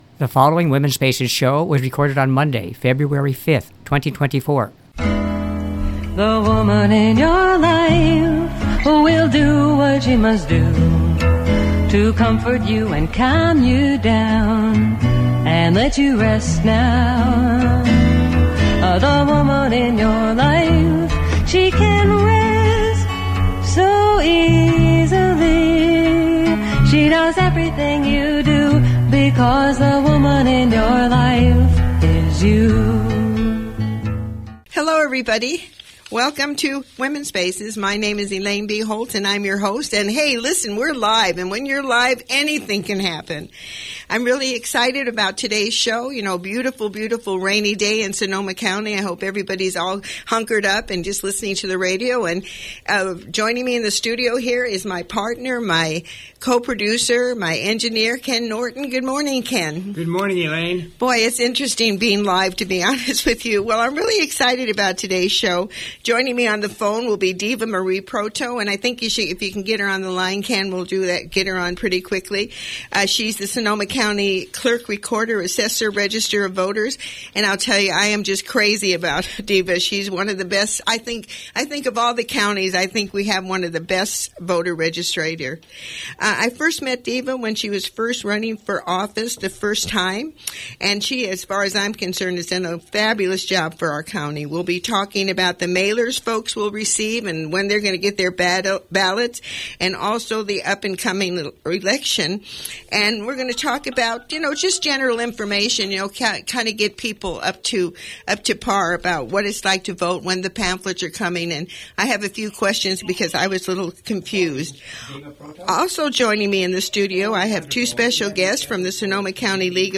Download the show Duration 57 minutes The Guest interview begins at 5 minutes.
Joining me on the phone will be Deva Marie Proto, who is Sonoma County’s Clerk-Recorder-Assessor-Registrar of Voters.